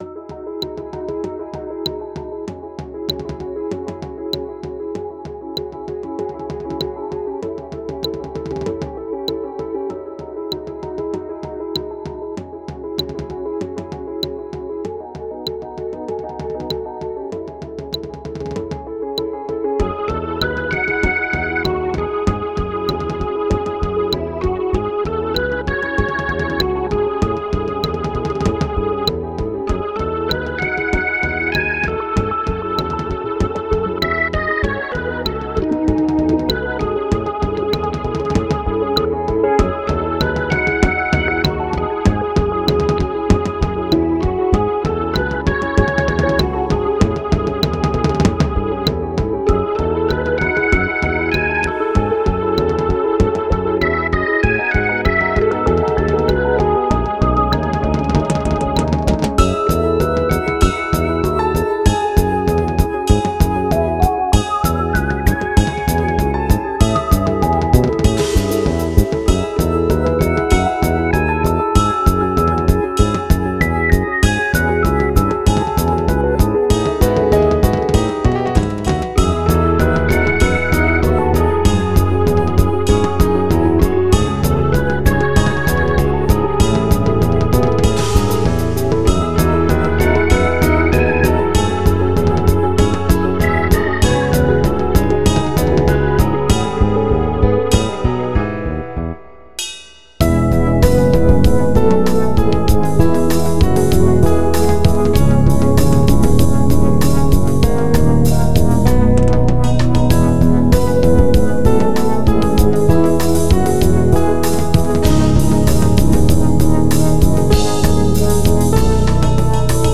This is music full of watery goodness. I wanted to have the feel of exploring some sort of ancient civilization that has completely underwater now. But from the drums I added, there might be more life in this civilization than I initially thought there would be.
It repeats once.